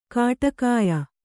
♪ kāṭakāya